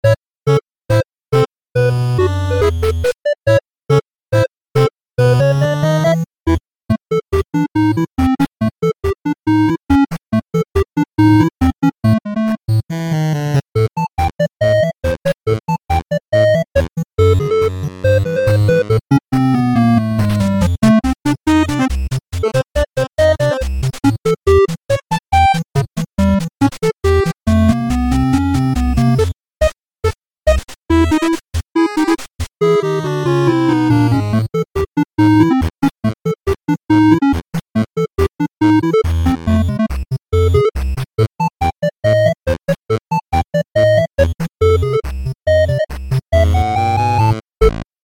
This is a music file.